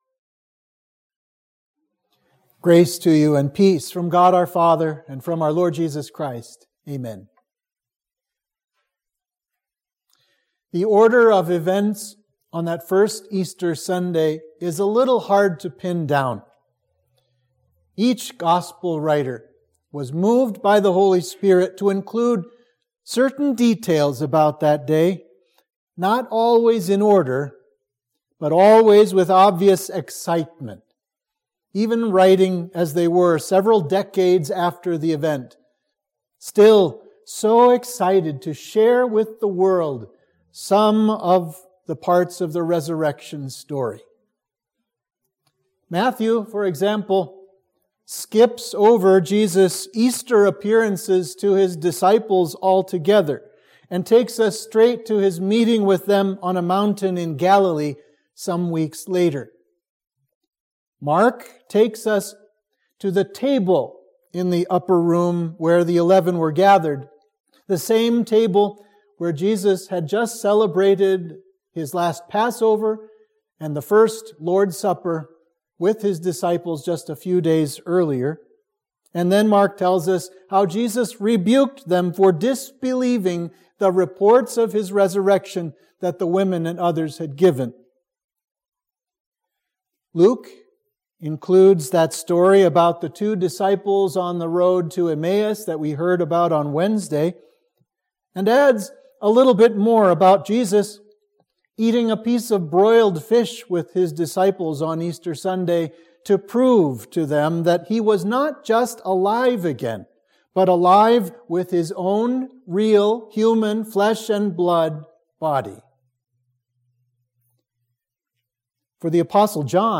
Sermon for Easter 1